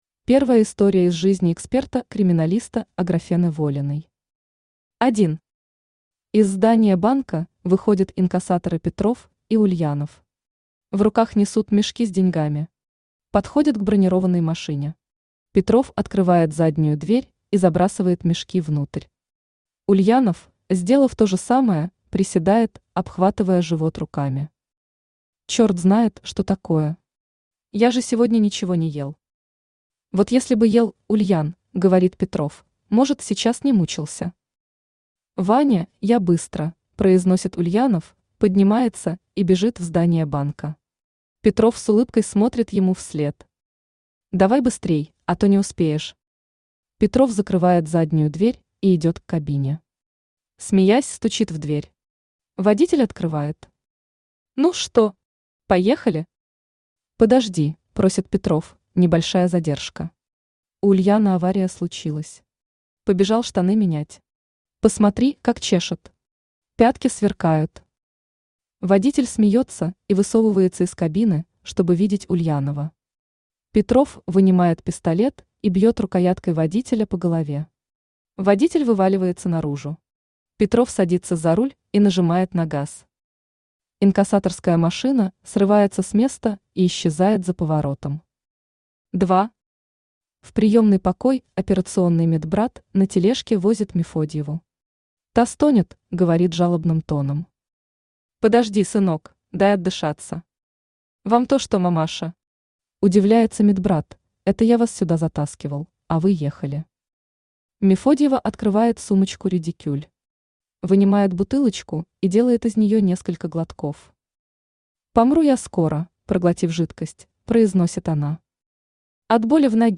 Аудиокнига Чупакабра. Ограбление | Библиотека аудиокниг
Ограбление Автор Сергей Алексеевич Глазков Читает аудиокнигу Авточтец ЛитРес.